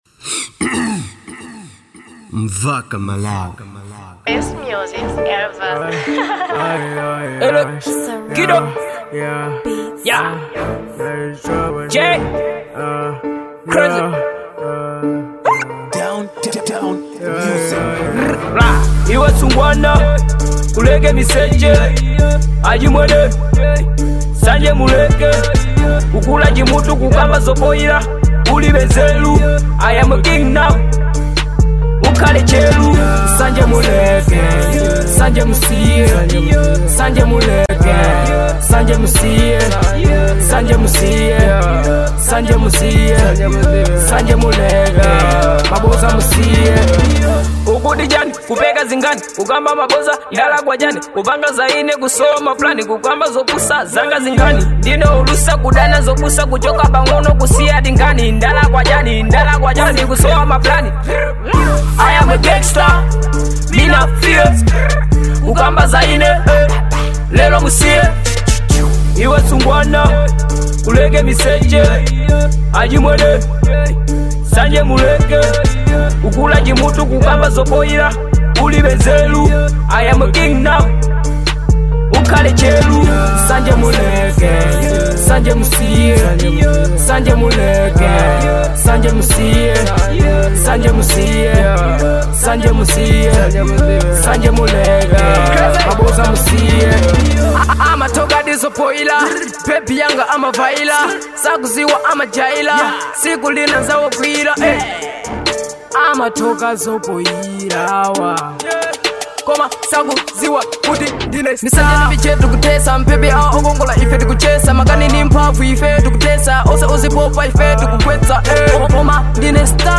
type: trap